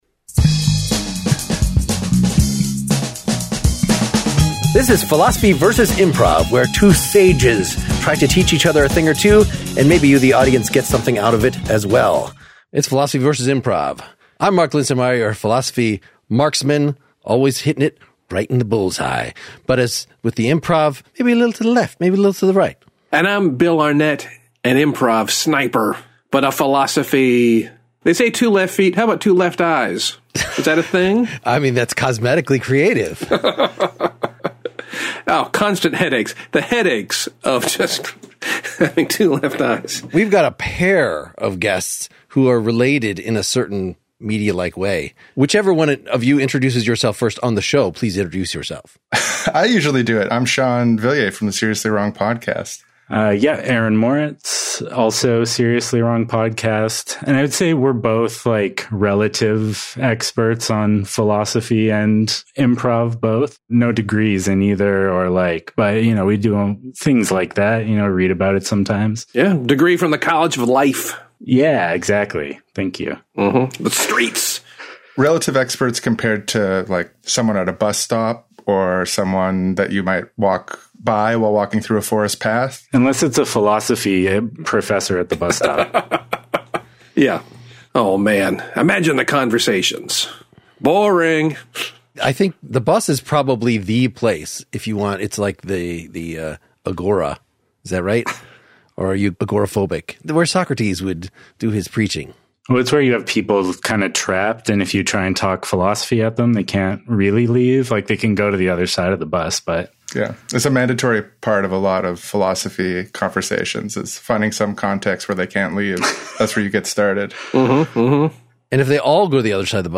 We simulate conversation as competing knowers-of-the-good-life and talk about using improv for political purposes. Note that this was recorded back in December when we were in the thick of Luigi Mangione fever and not yet consumed with daily Presidential antics.